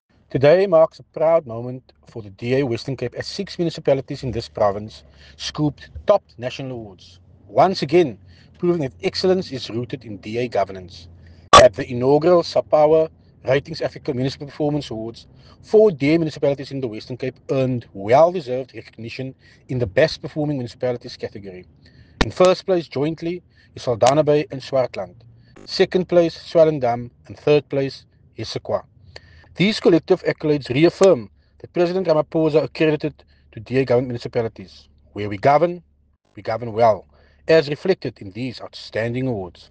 soundbite by Tertuis Simmers